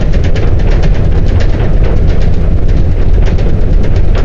Cockpit_Roll.wav